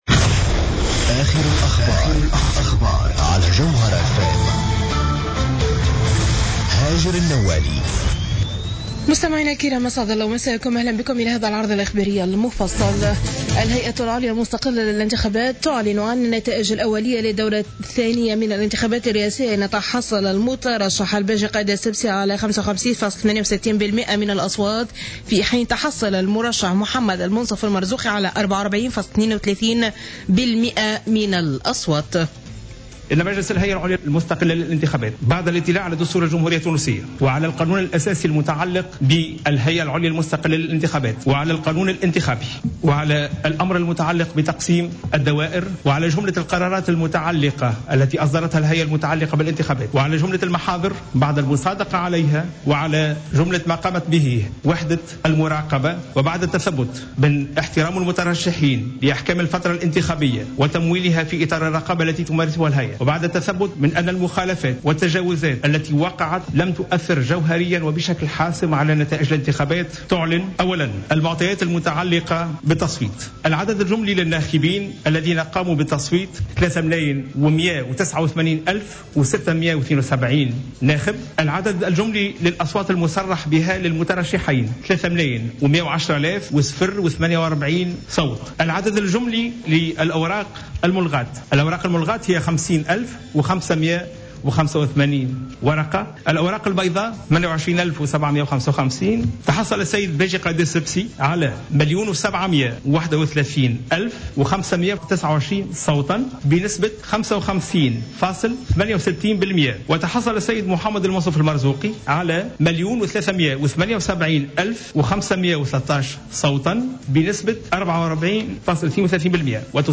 نشرة اخبار منتصف الليل ليوم الثلاثاء 23 ديسمبر 2014